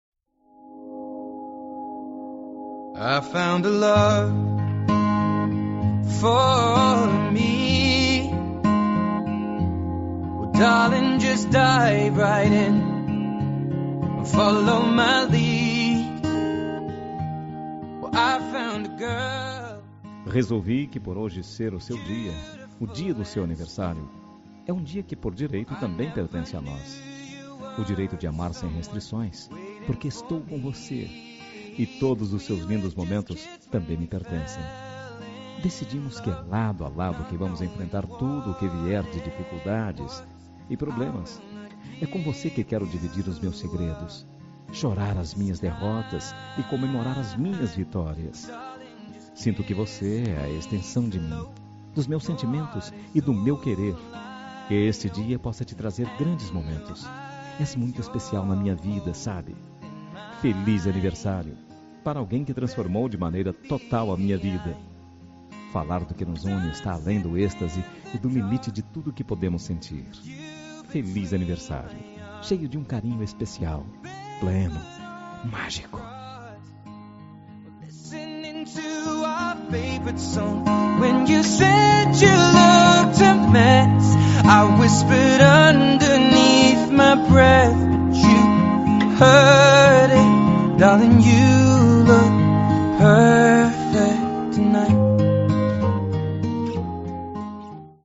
Telemensagem de Aniversário Romântico – Voz Masculina- Cód: 202132